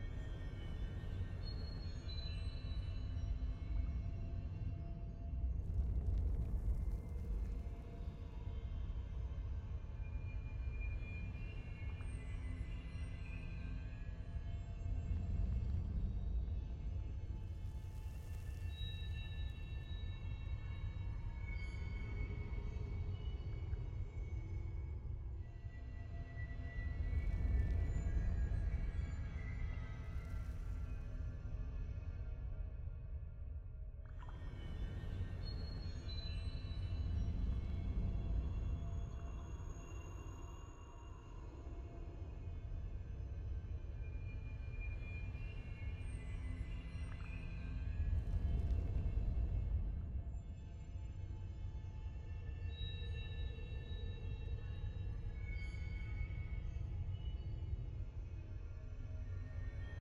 sfx-ambience-loop-tft-set17.ogg